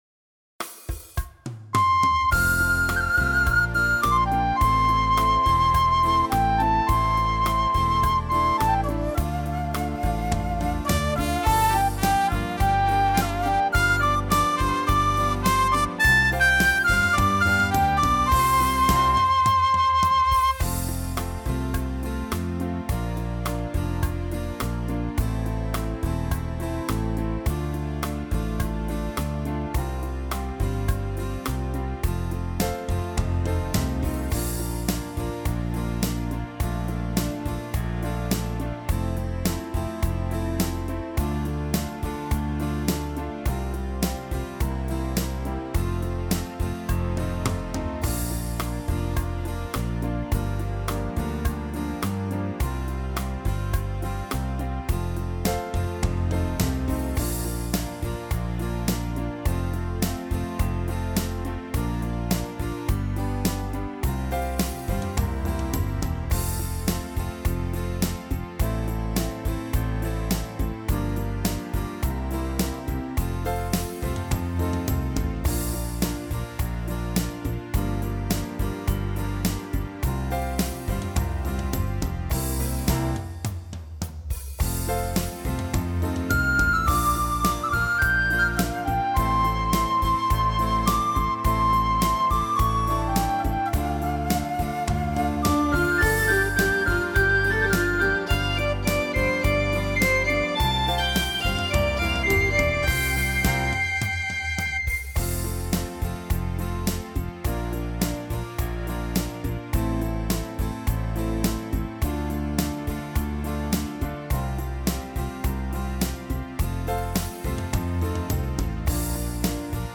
•   Beat  03.